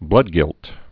(blŭdgĭlt)